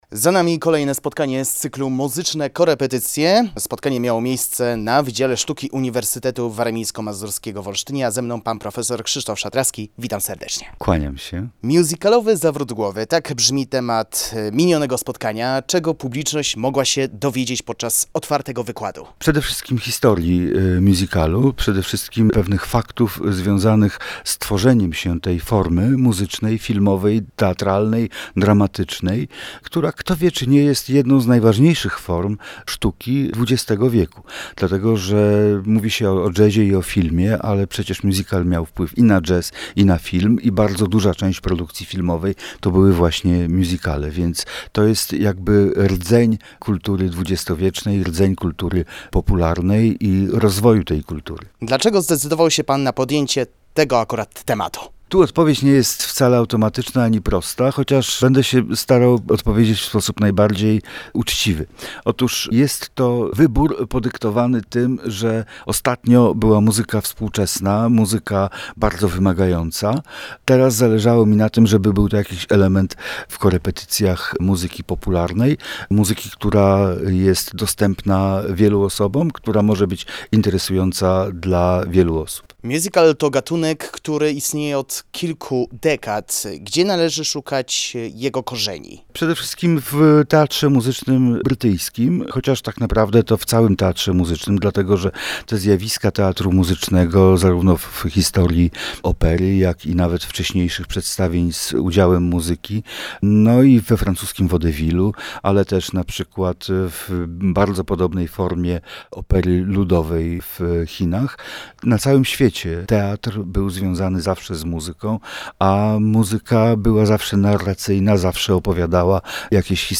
rozmowy